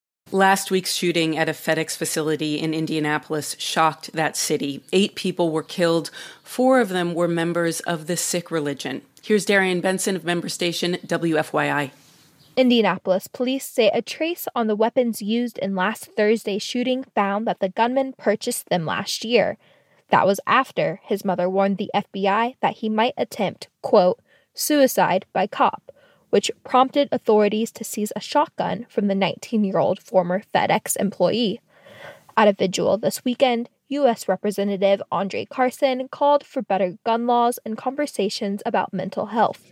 NPR_News_Indianapolis-Shooter.mp3